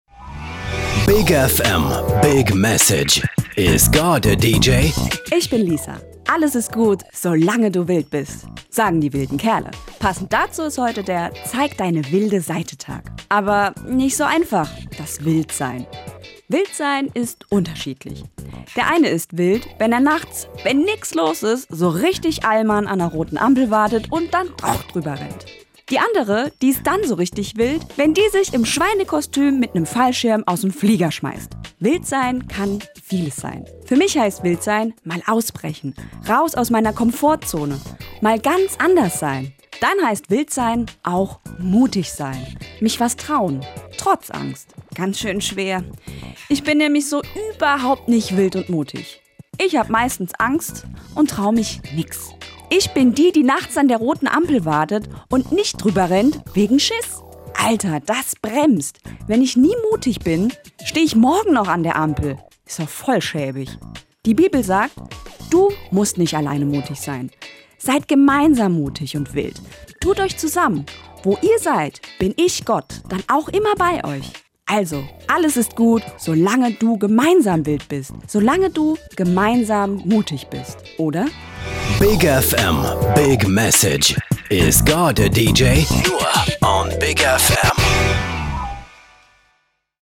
ProtCast Pfalz - Radioandachten aus Rheinland-Pfalz